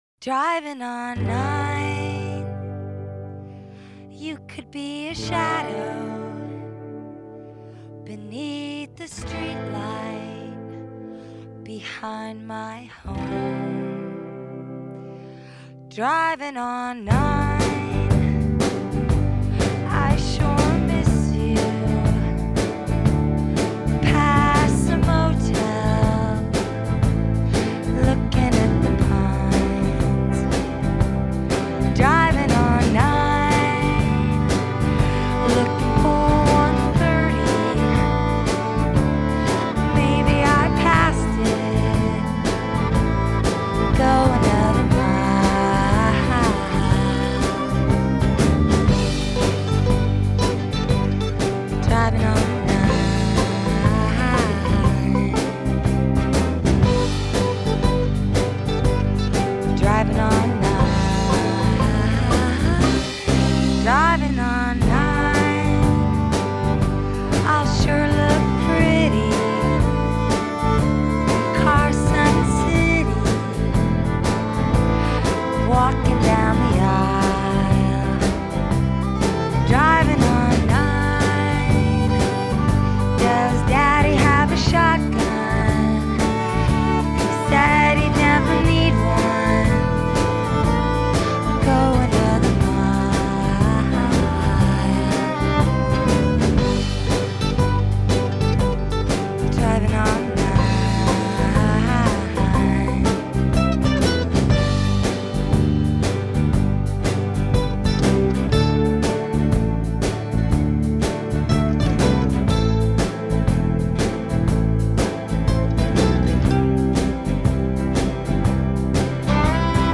Инди рок